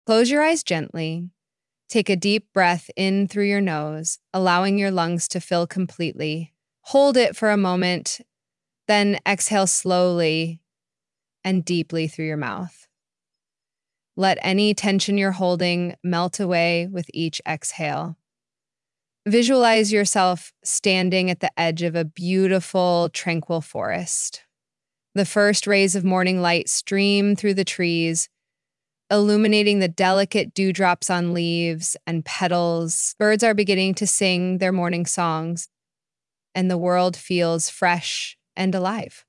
conversational-tts emotion-tts multi-speaker-tts multi-voice-dialogue multilingual text-to-speech
End-to-end AI speech model designed for natural-sounding conversational speech synthesis, with support for context-aware prosody, intonation, and emotional expression.
"voice": "Nia (Young female US conversational voice)",